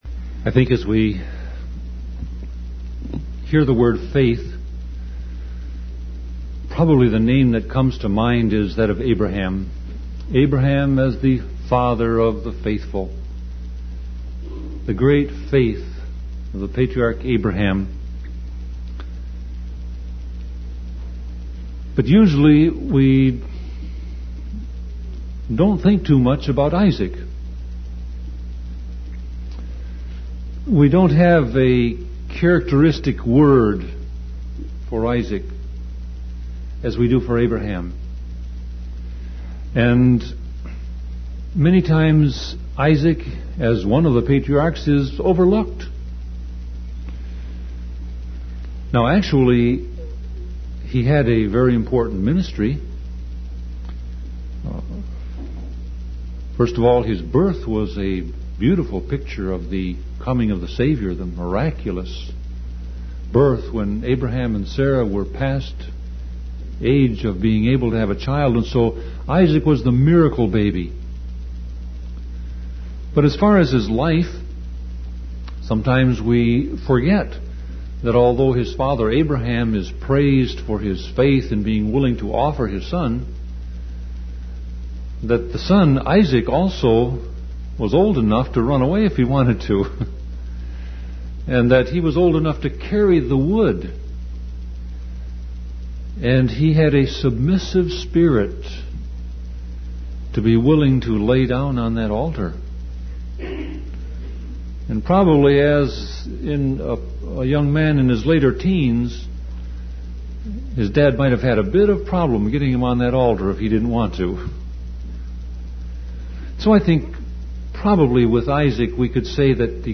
Series: Sermon Audio Passage: Genesis 26 Service Type